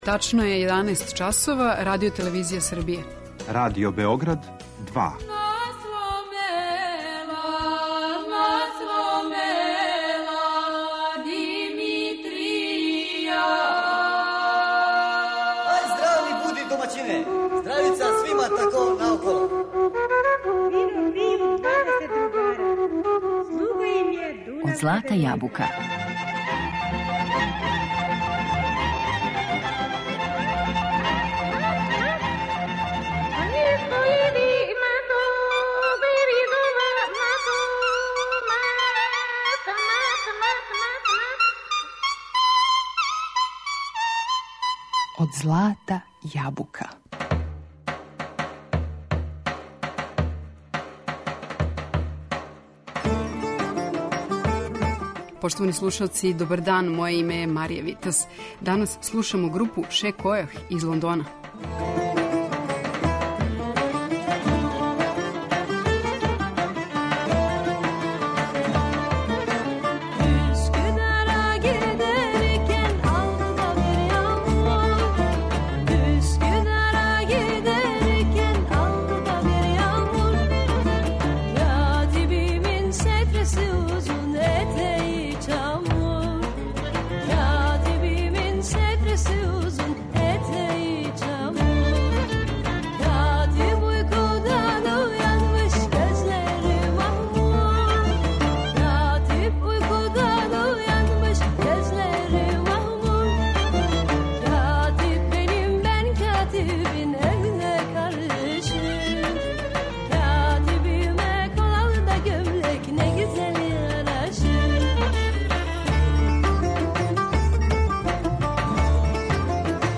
Чућемо, између осталог, како један мултинационални британски састав тумачи мелодије попут „Русе косе", „Копано хоро", „Сила кале бал", „Мој дилбере", „Јасеничко коло", „Ромска поскочица" итд.